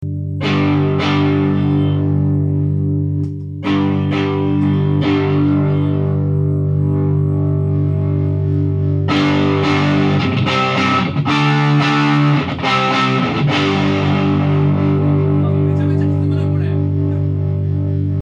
録音したMDから雑音のひどいものを取り除き、アップだば。